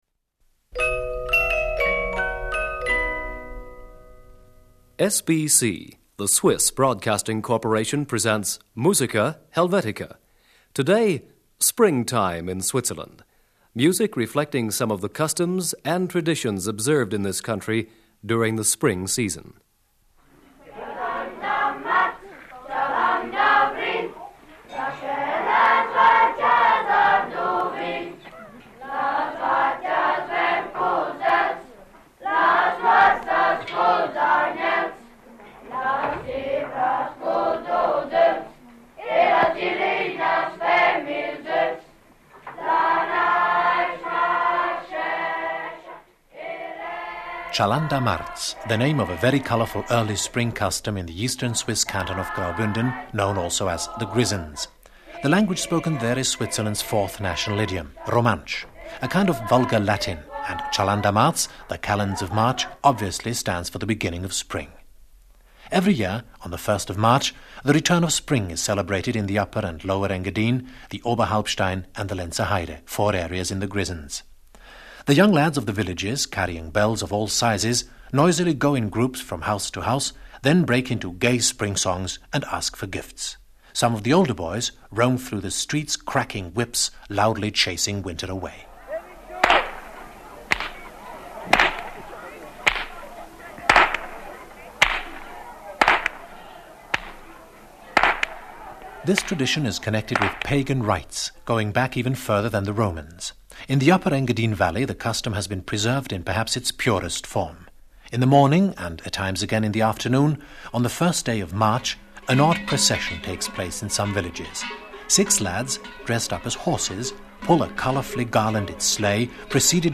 Swiss Folk Music. Springtime in Switzerland.
Whip cracking.
Bell ringing.